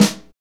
Index of /90_sSampleCDs/Northstar - Drumscapes Roland/SNR_Snares 1/SNR_Motown Snrsx
SNR MTWN 04L.wav